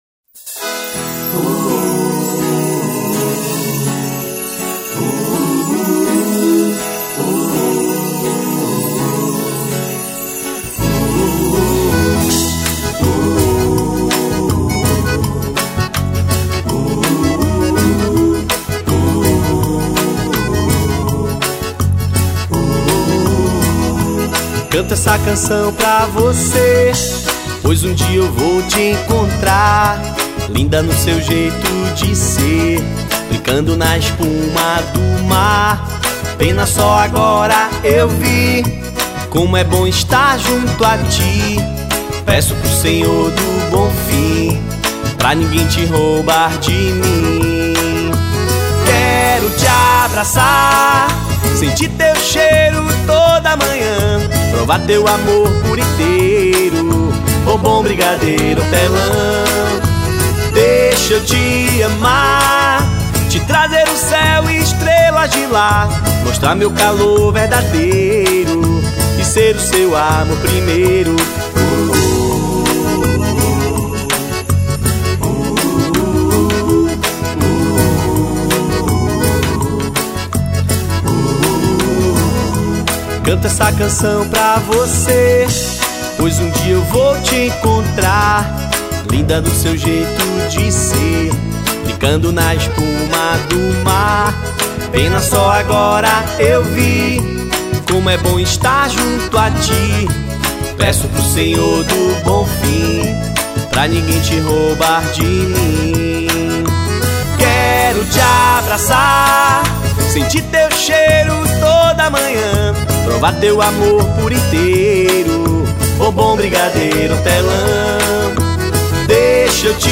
EstiloForró
Cidade/EstadoSalvador / BA